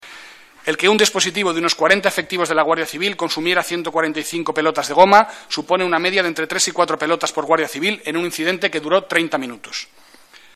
Comparecencia del Secretario de Estado de Seguridad sobre la muerte de 15 inmigrantes en Ceuta 19/03/2014